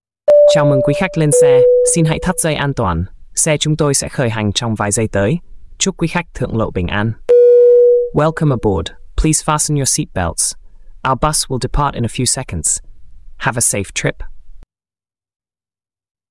Thể loại: Tiếng xe cộ
Description: Tải file âm thanh Chào mừng quý khách lên xe giọng nam mp3 đang trend viral trên TikTok: Chào mừng quý khách lên xe!